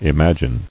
[i·mag·ine]